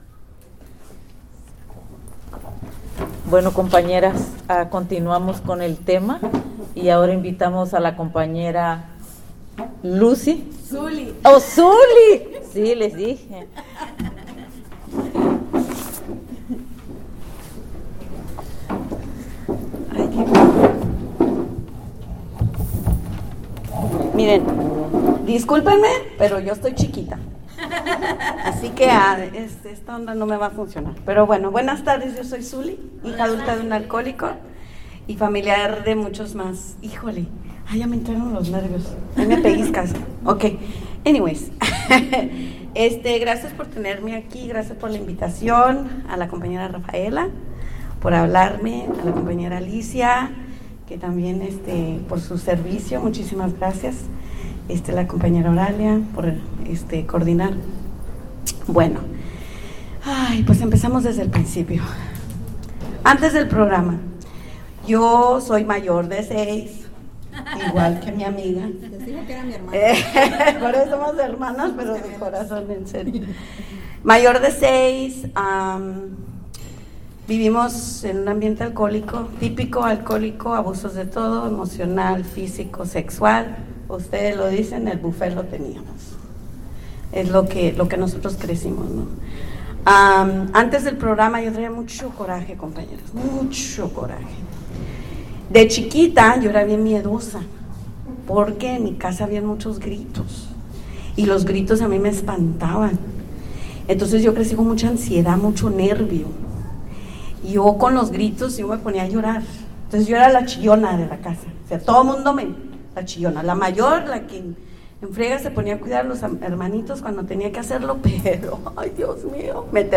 35th Annual Ventura Serenity By The Sea
Spanish Speaker